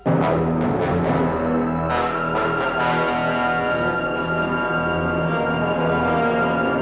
opening cue